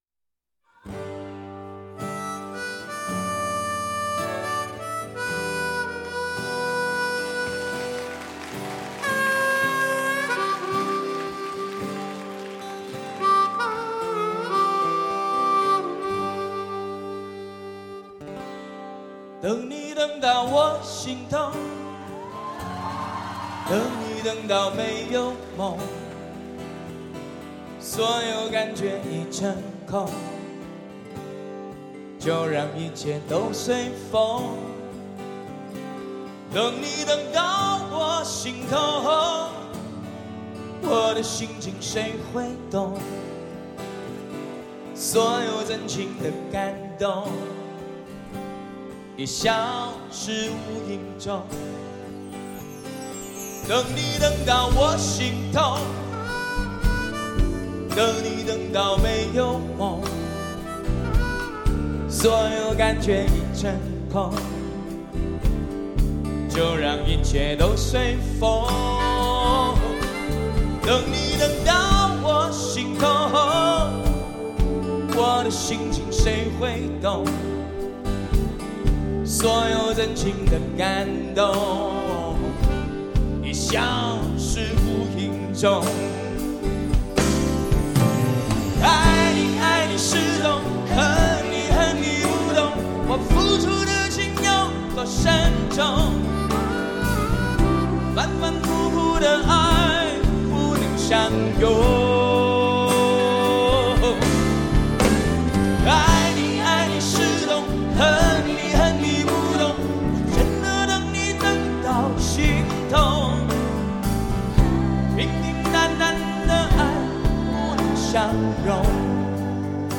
国际级超大型25人乐手倾情演出
HiFi教父HiFi德监控 极尽发烧现场直接录音